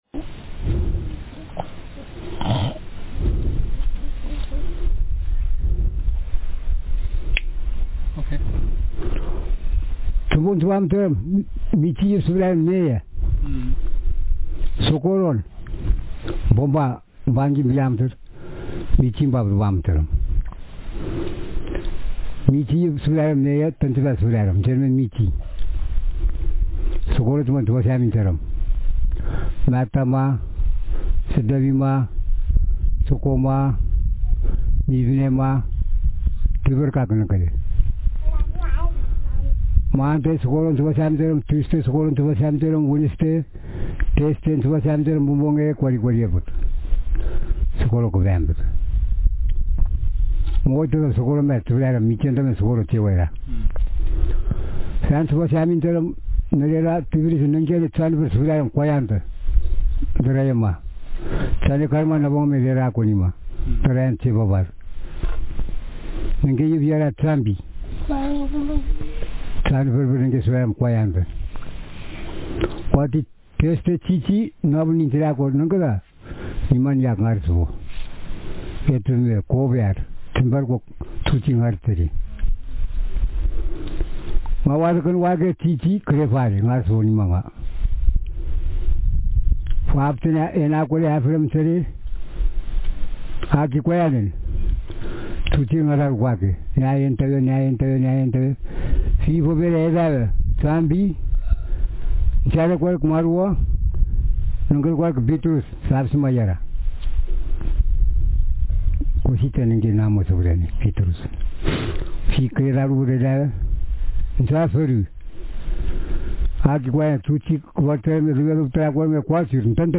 Speaker sex m Text genre personal narrative